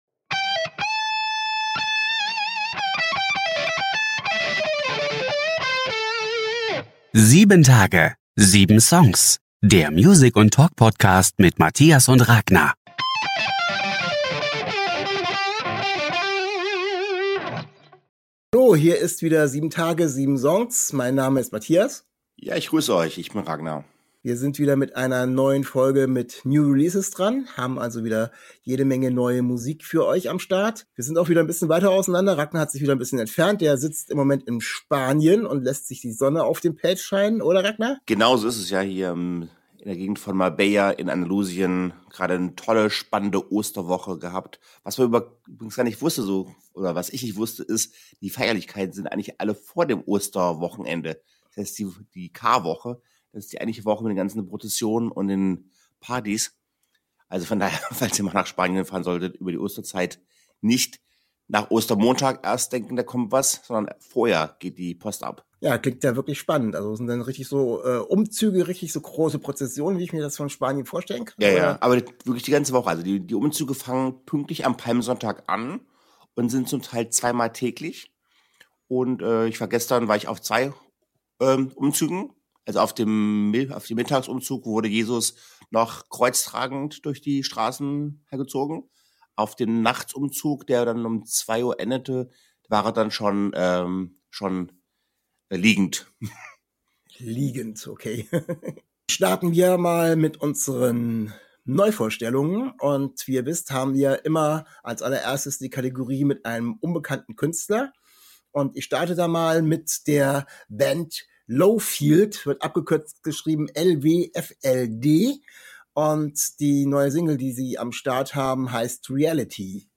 Neue Songs der Woche #35: 7 Tage - 7 Songs: Music + Talk Podcast ~ 7 Tage 7 Songs Podcast